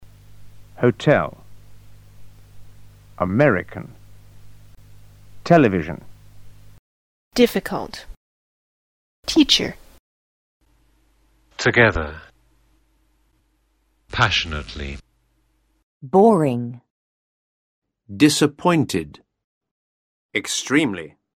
word_stress_04.mp3